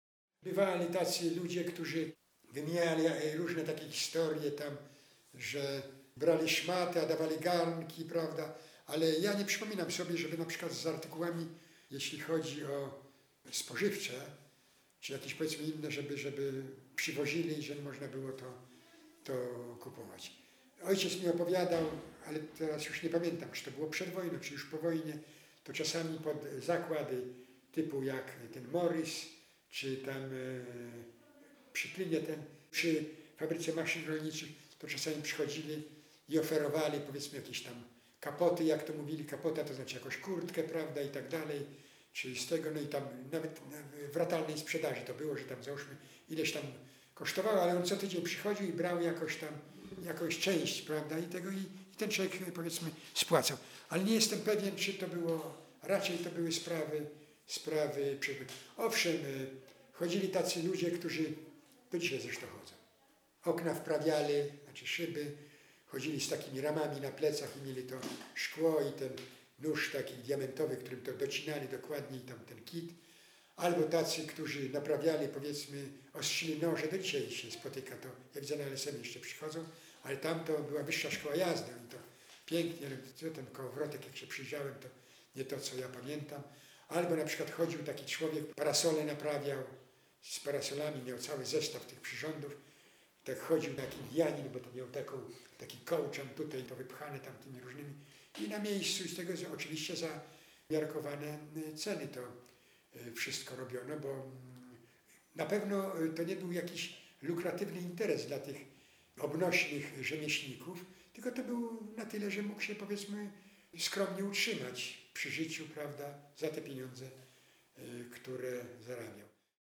fragment relacji świadka historii
Relacja mówiona zarejestrowana w ramach Programu Historia Mówiona realizowanego w Ośrodku